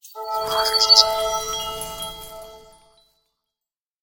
Звучание светящегося подарка